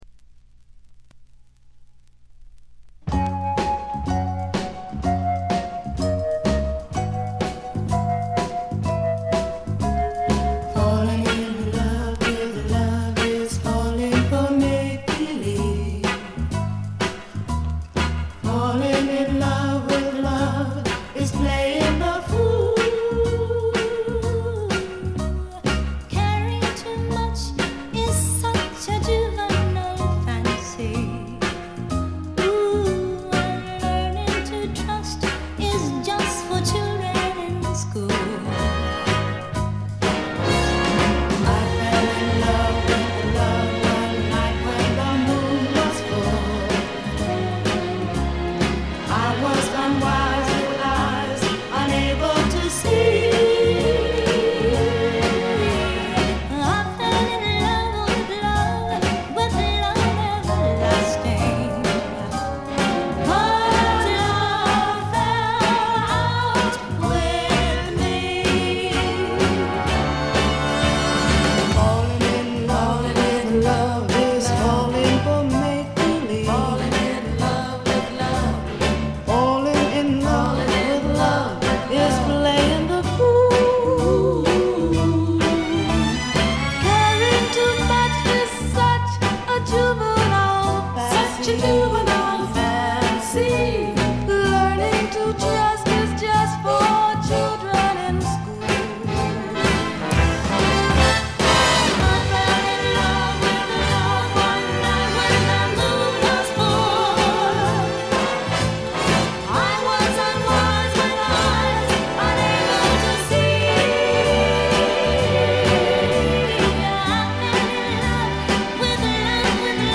ほとんどノイズ感無し。
モノプレス。
試聴曲は現品からの取り込み音源です。